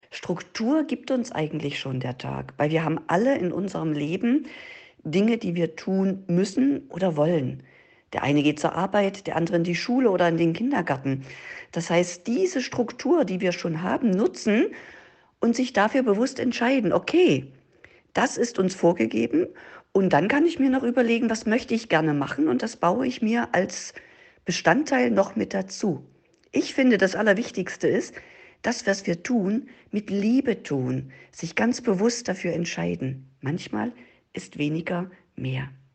radioEXPERTEN - Ihr perfekter Interviewpartner